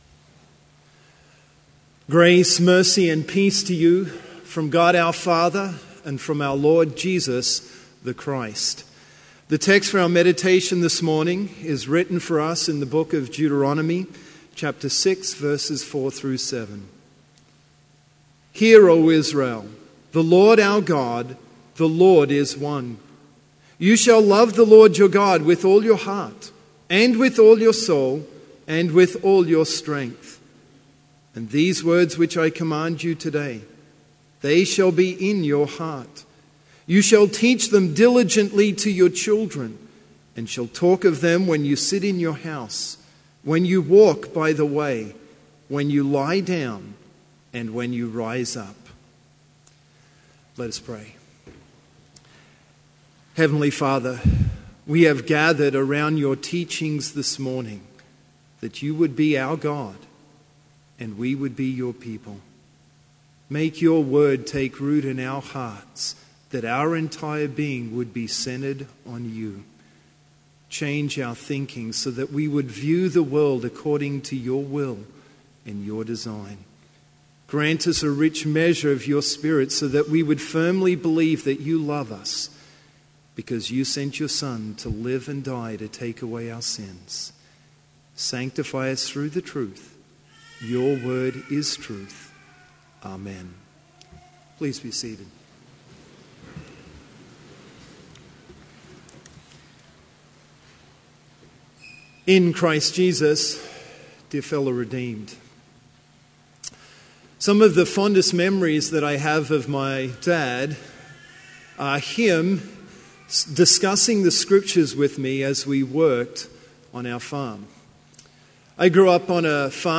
Complete service audio for Chapel - September 7, 2016